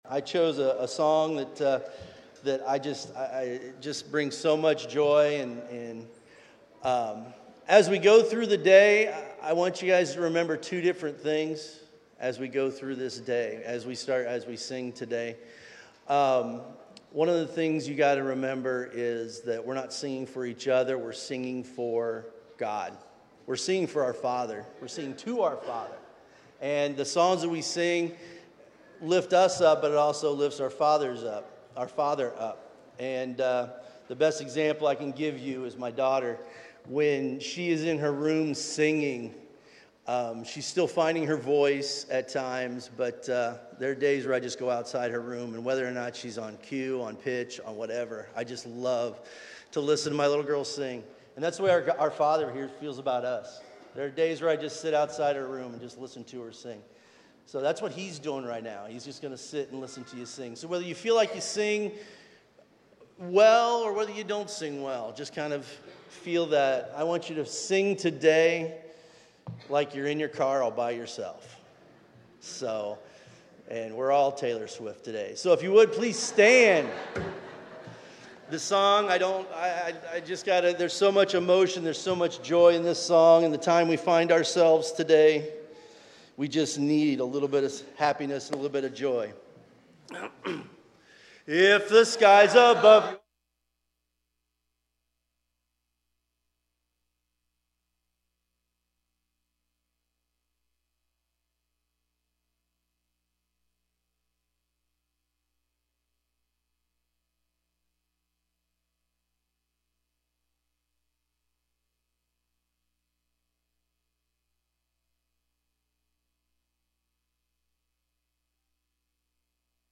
9/29/2024 Praise and Worship By NTCOFC
Recording from North Tampa Church of Christ in Lutz, Florida.